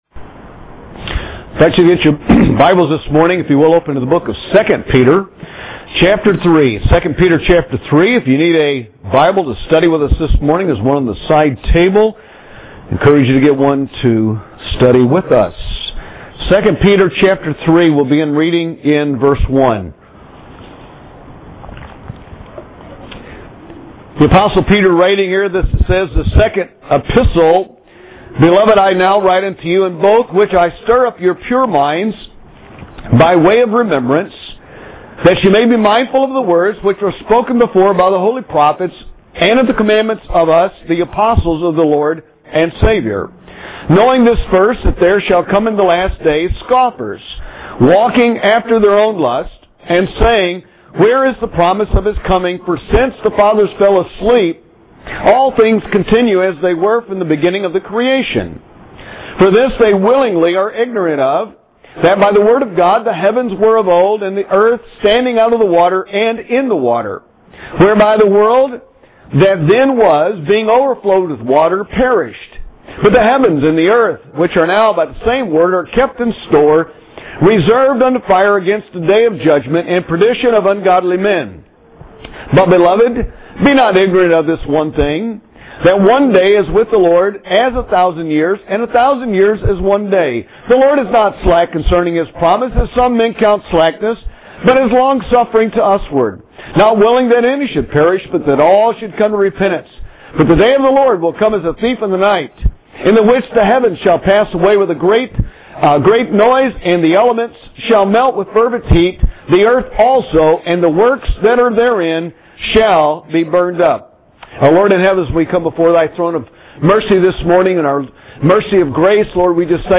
Recorded Sermons Archives - Page 41 of 47 - Cornerstone Baptist Church